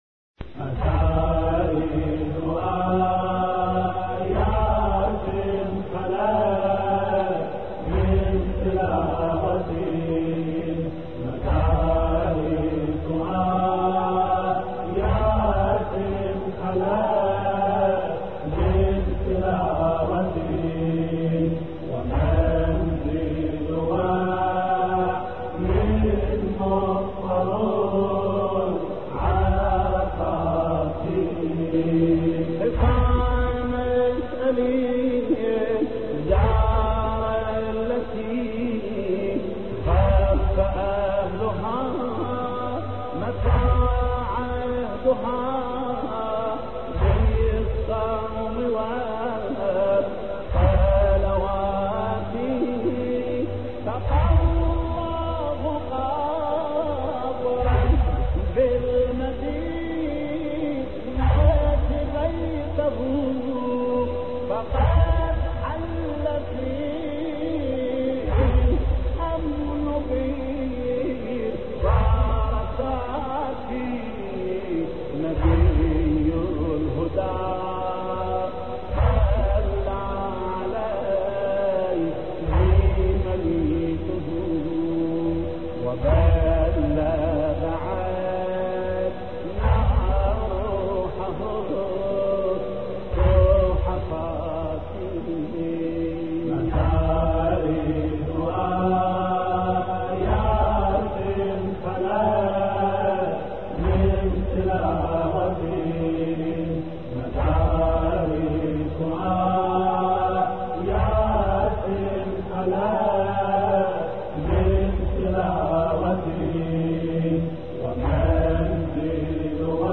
مراثي الامام الرضا (ع)